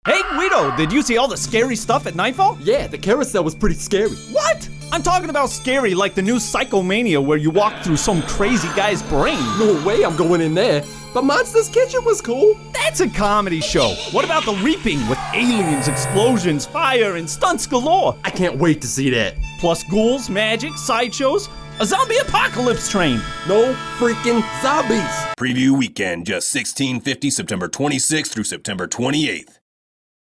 NIGHTFALL RADIO SPOT